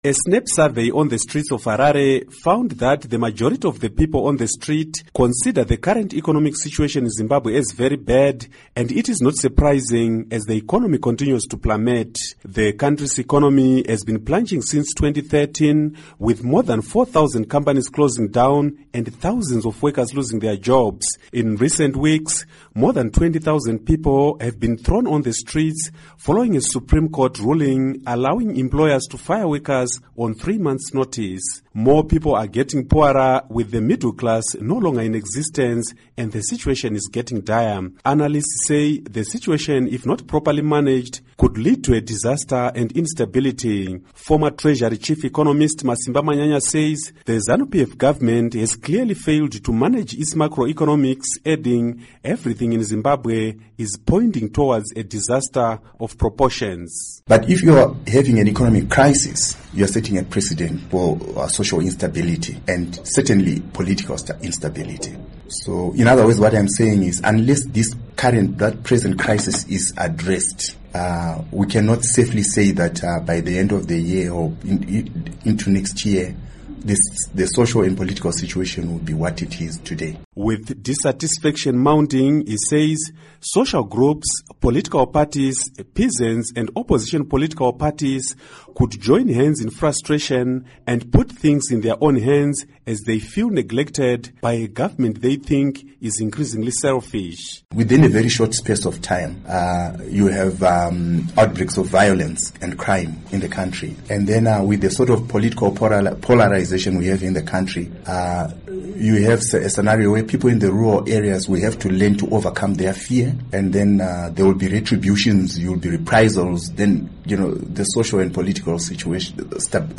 Report on Zimbabwe Economy